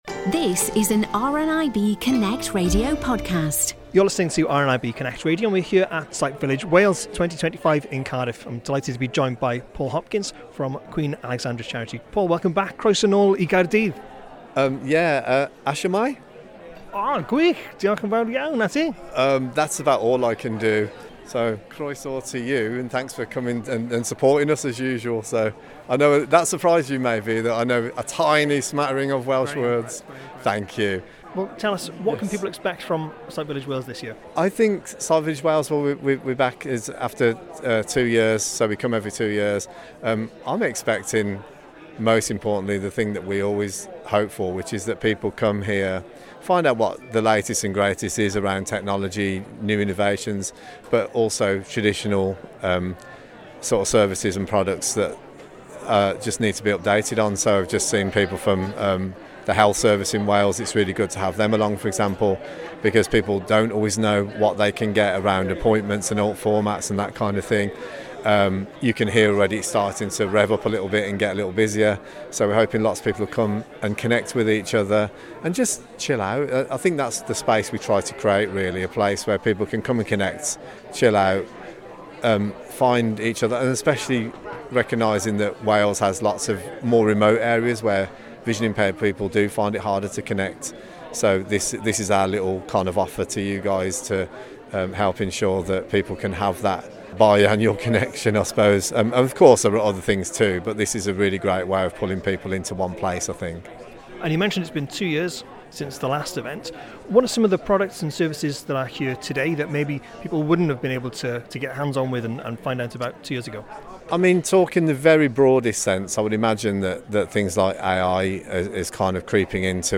The Sight Village Wales exhibition returned to Cardiff on April the 8th.